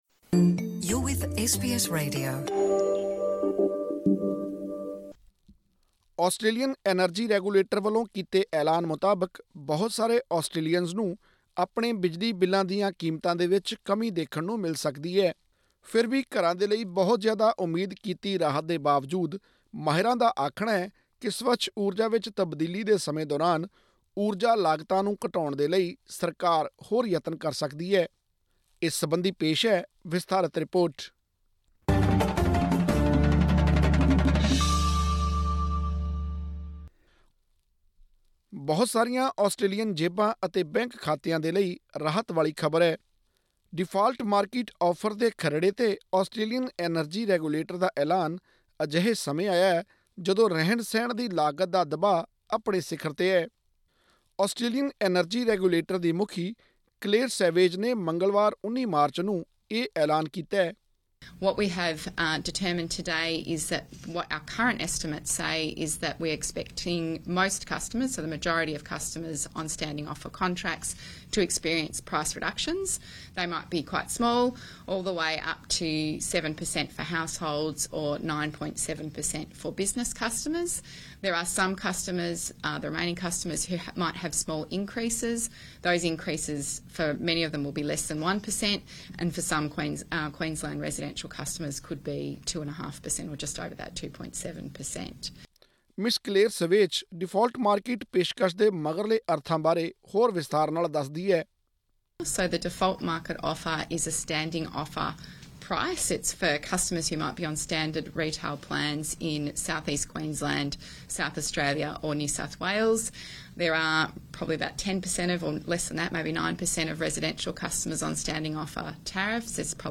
ਹੋਰ ਵੇਰਵੇ ਲਈ ਸੁਣੋ ਆਡੀਓ ਰਿਪੋਰਟ